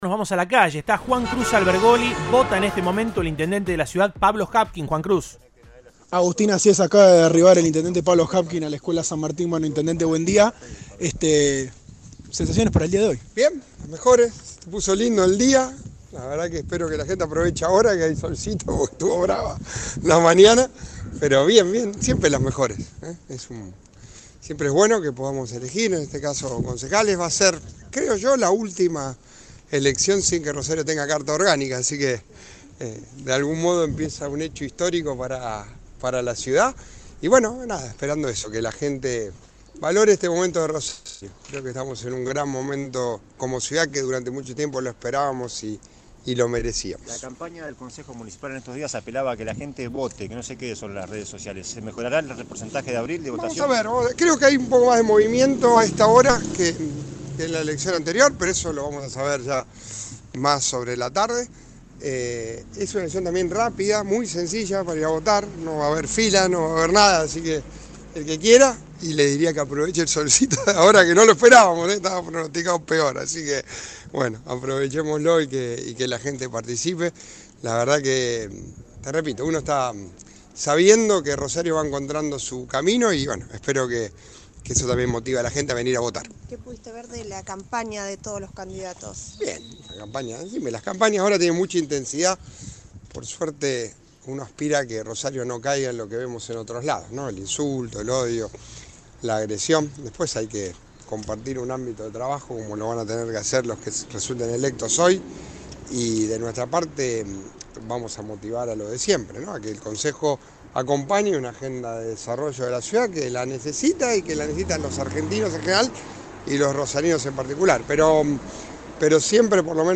Audio. Elecciones en Rosario. Habló el intendente Pablo Javkin.
El intendente de Rosario, Pablo Javkin, llegó este viernes a la Escuela San Martín para ejercer su derecho al voto en las elecciones de concejales.